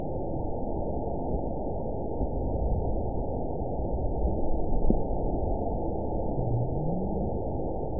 event 917274 date 03/26/23 time 14:18:38 GMT (2 years, 1 month ago) score 9.39 location TSS-AB04 detected by nrw target species NRW annotations +NRW Spectrogram: Frequency (kHz) vs. Time (s) audio not available .wav